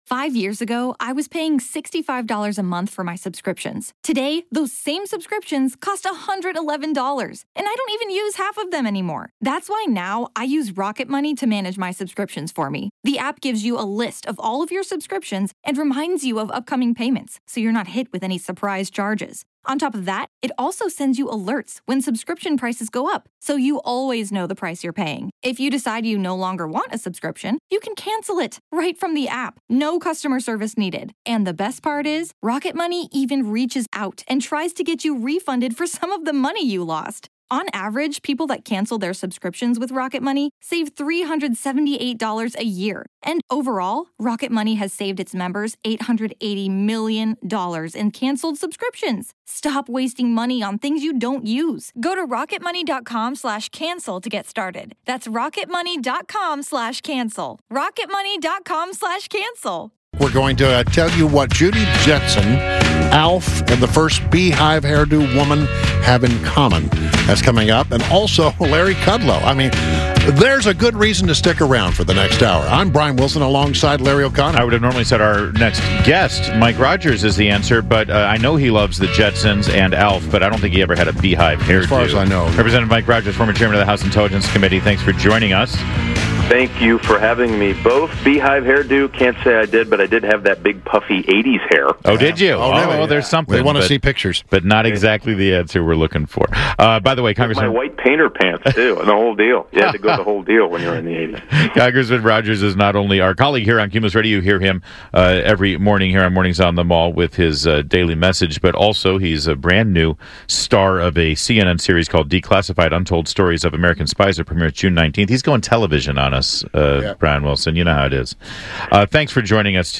WMAL INTERVIEW -REP. MIKE ROGERS - 06.14.16